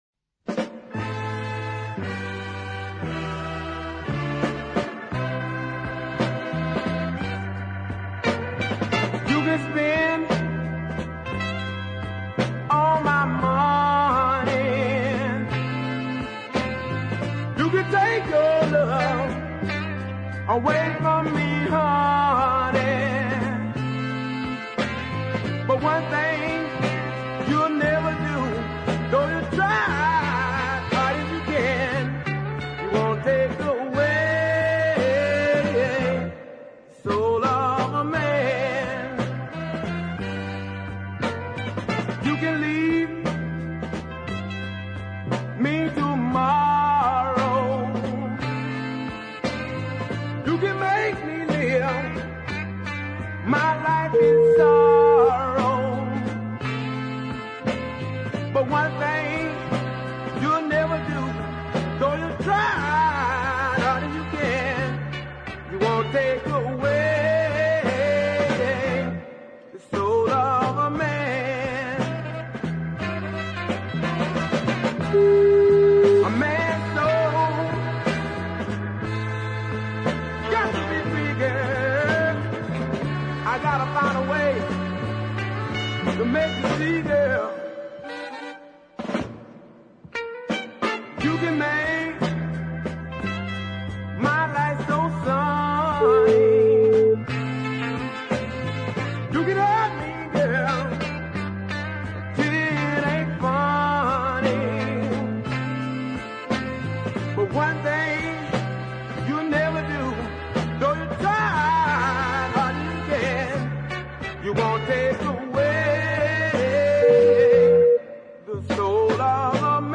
great deep soul effort